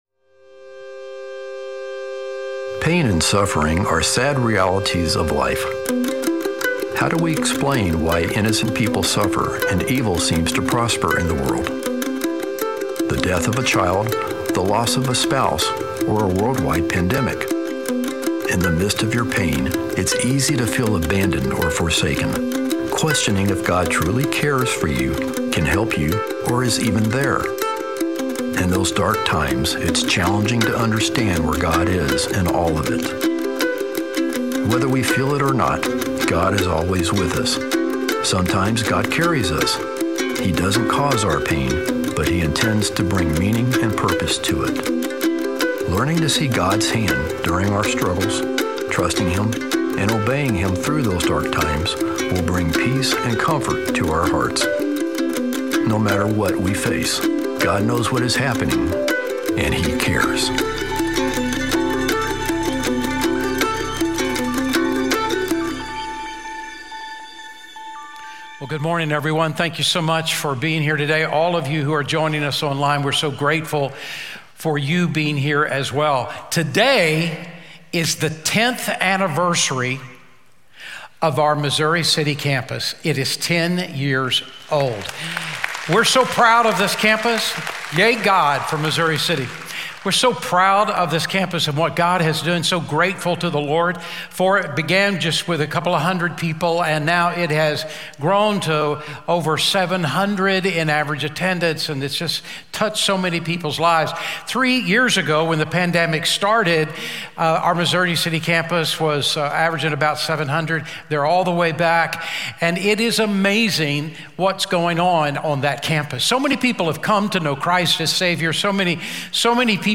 Here you can watch Sugar Creek sermons live online, or look back at previous sermons.